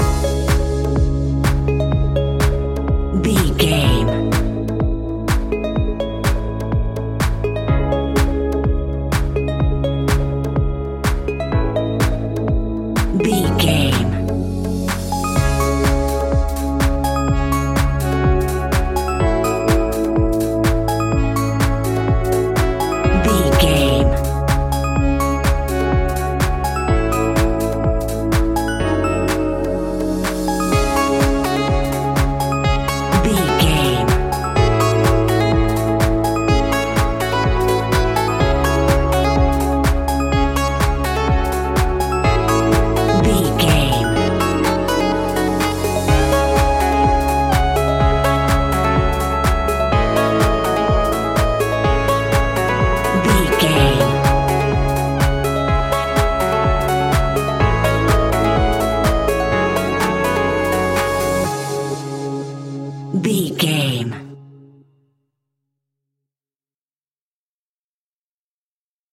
Aeolian/Minor
C#
groovy
hypnotic
dreamy
smooth
synthesiser
drum machine
electric guitar
funky house
deep house
nu disco
upbeat
funky guitar
synth bass